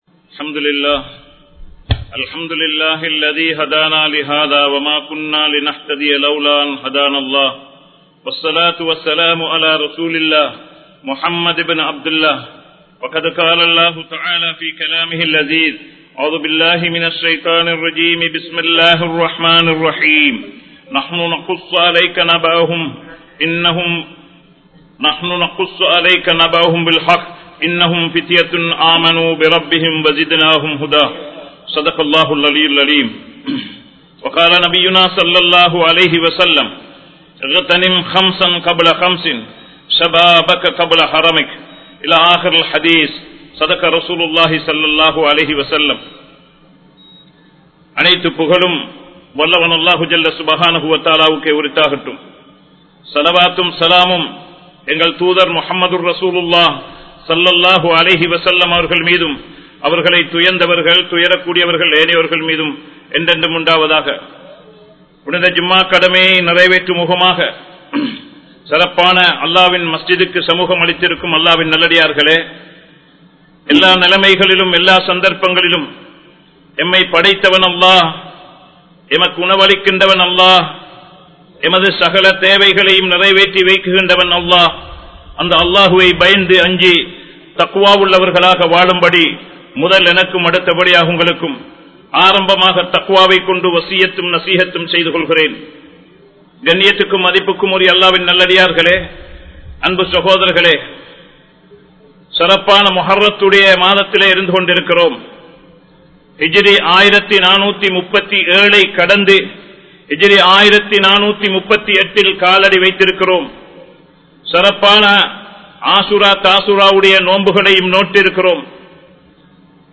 Samoohathil Inriya Valiparkalin Nilai(சமூகத்தில் இன்றைய வாலிபர்களின் நிலை) | Audio Bayans | All Ceylon Muslim Youth Community | Addalaichenai